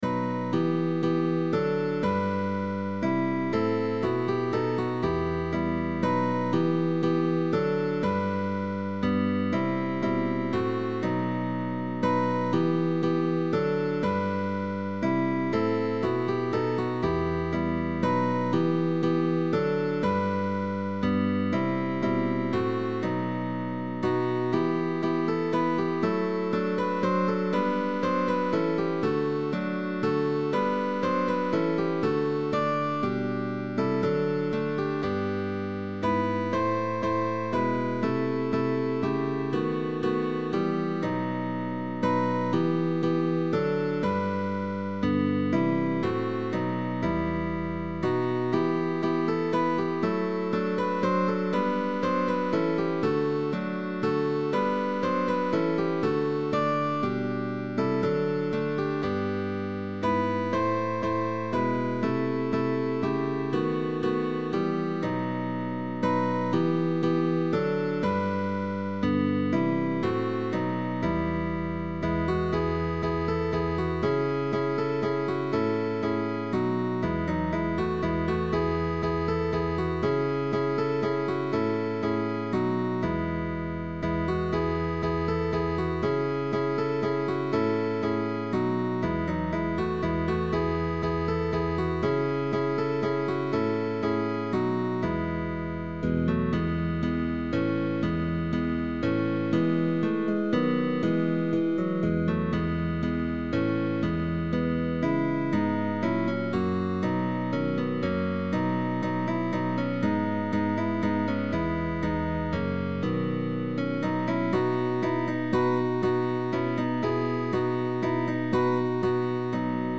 CUARTETO DE GUITARRAS
cuarteto de guitarras